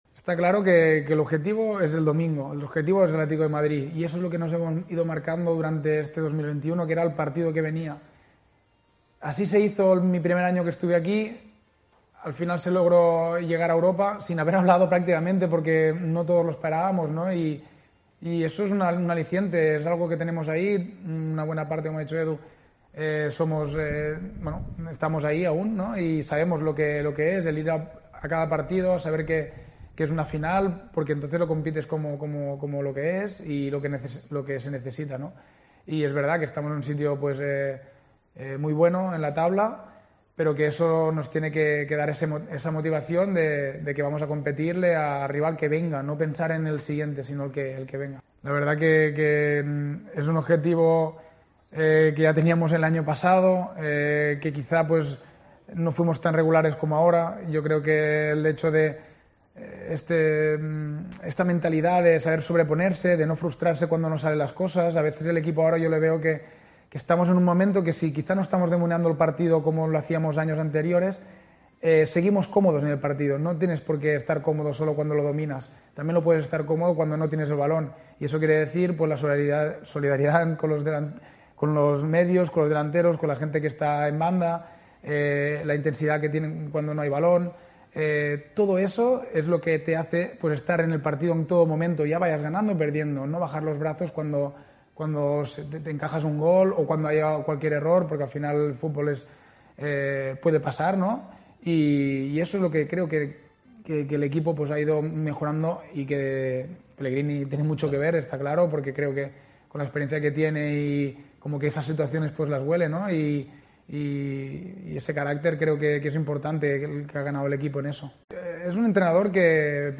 MARC BARTRA EN UNA ENTREVISTA EN LOS MEDIOS OFICIALES DEL CLUB